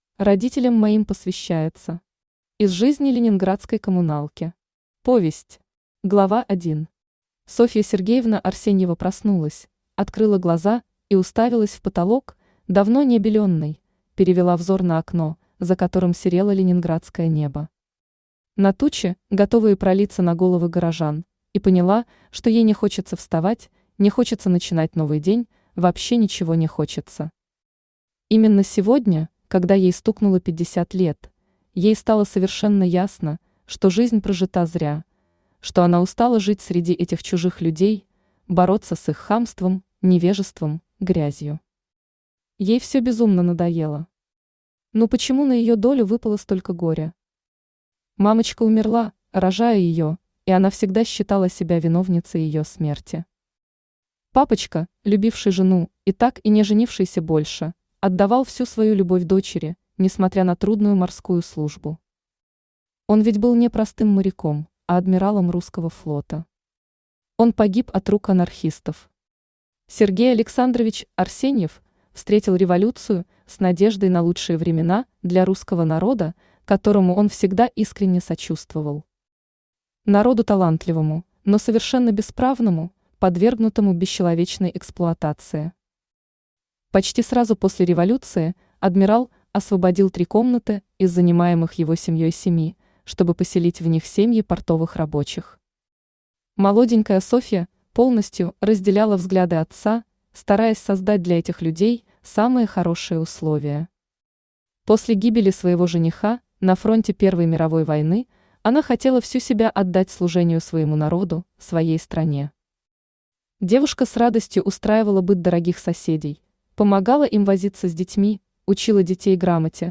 Аудиокнига Была только любовь. Воспоминания о блокаде | Библиотека аудиокниг
Воспоминания о блокаде Автор Жанна Светлова Читает аудиокнигу Авточтец ЛитРес.